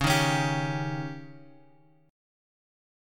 C#sus2b5 chord {x 4 1 x 2 3} chord